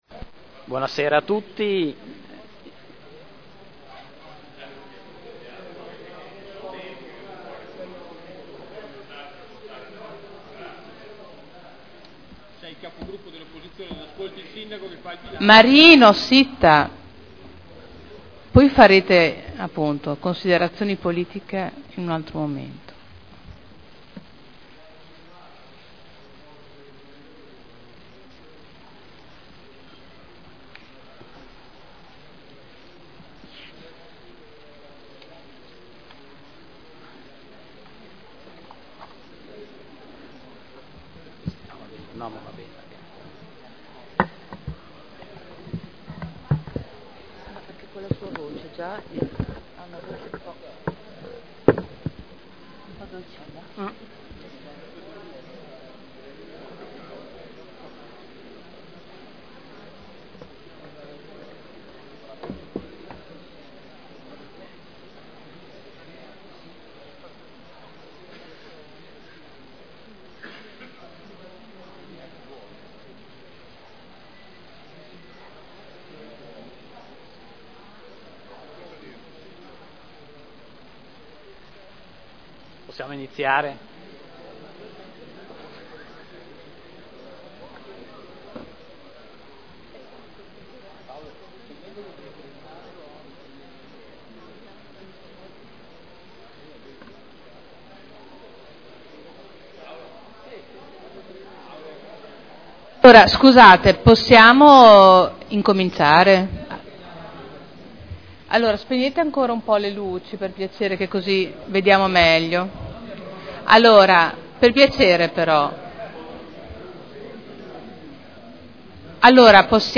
Seduta del 24/02/2011. Relazione Previsionale e Programmatica - Bilancio pluriennale 2011/2013 - Bilancio preventivo per l’esercizio finanziario 2011 - Programma triennale dei Lavori Pubblici 2011/2013 - Presentazione del Sindaco e dell’Assessore alle Politiche Finanziarie e Partecipazione.